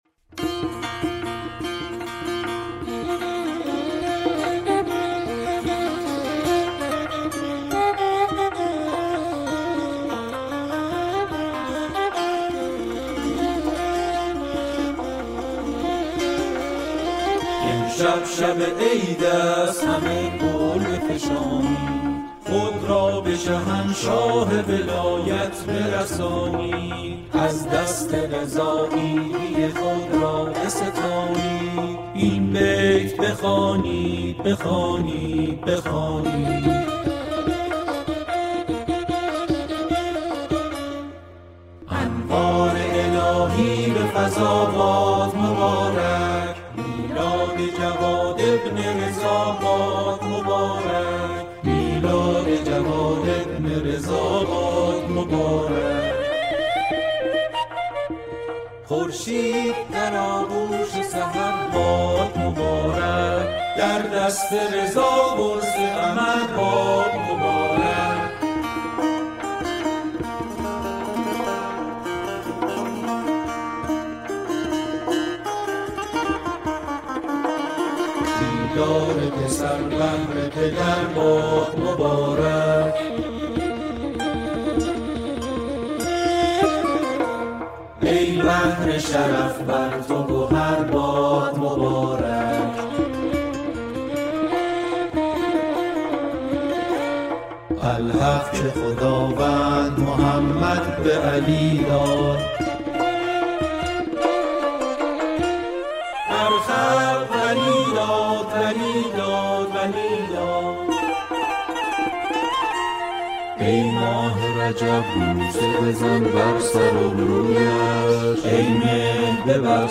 سرودهای امام جواد علیه السلام